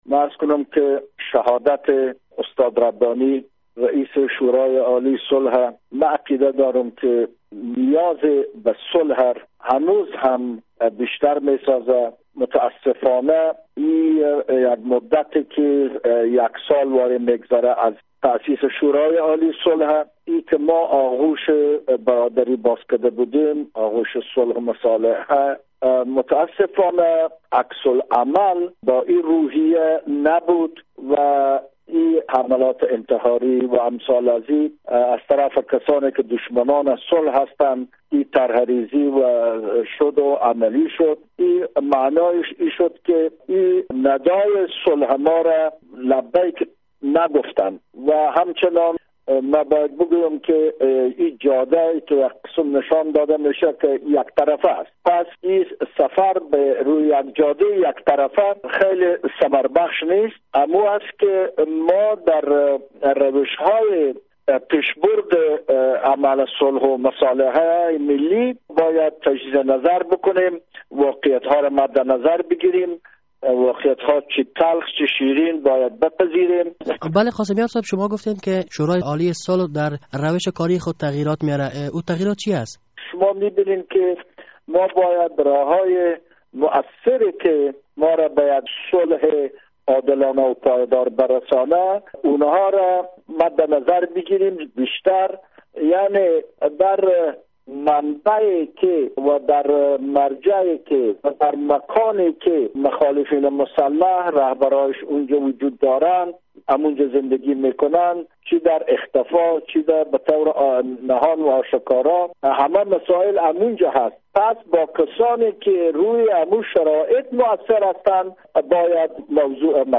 مصاحبه با اسماعیل قاسمیار عضو شورای عالی صلح افغانستان